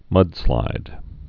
(mŭdslīd)